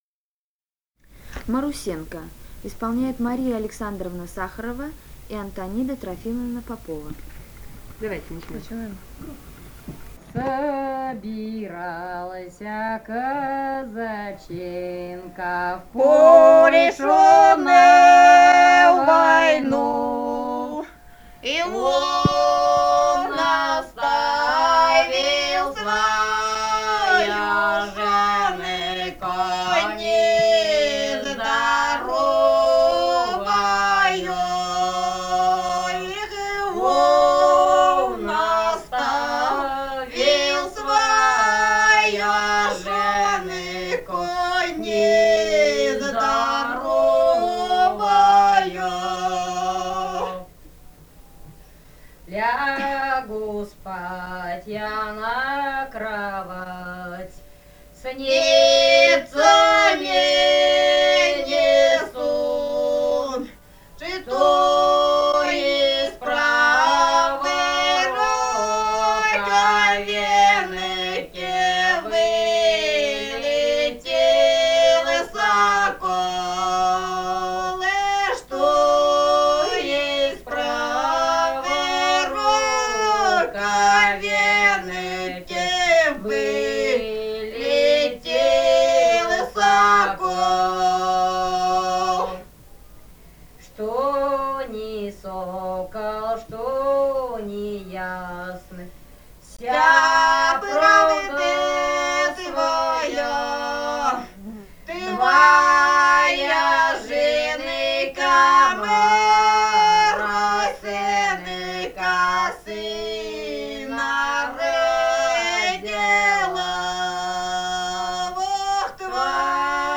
Этномузыкологические исследования и полевые материалы
Бурятия, с. Харацай Закаменского района, 1966 г. И0905-05